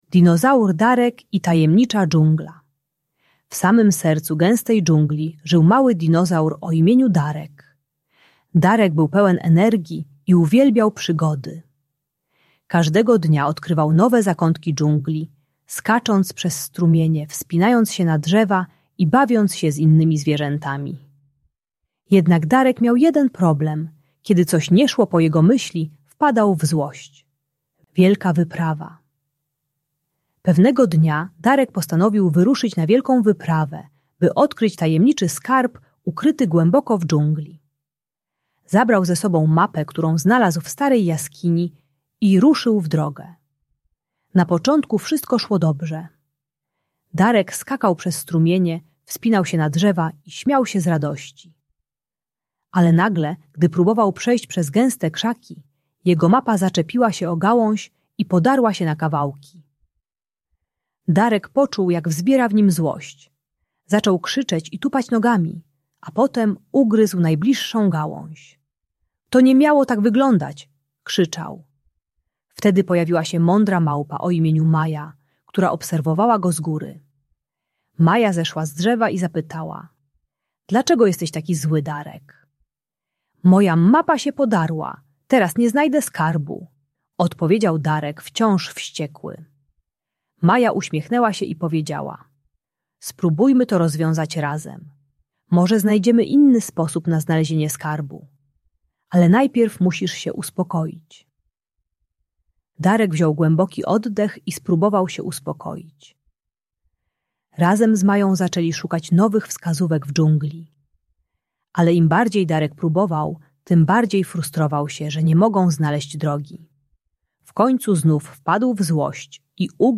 Dinozaur Darek i Tajemnicza Dżungla - Bunt i wybuchy złości | Audiobajka